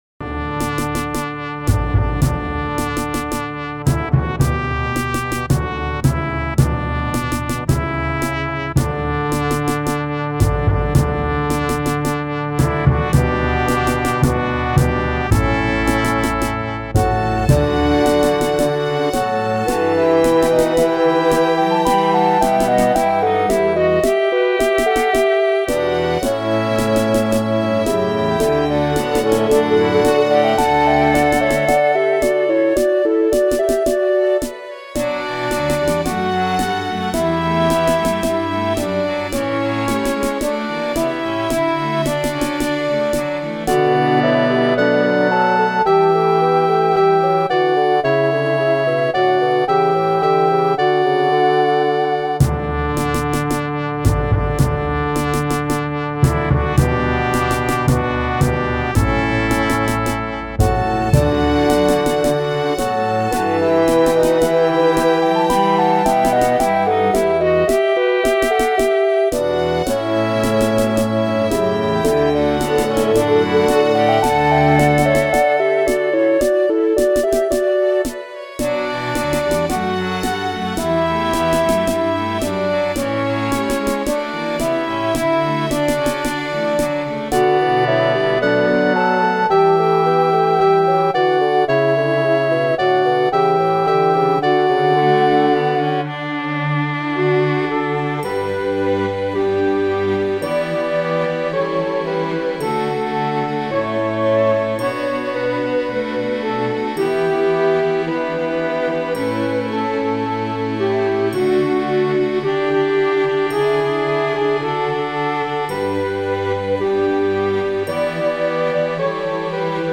with a wonderful 12 part, full orchestral MIDI arrangement.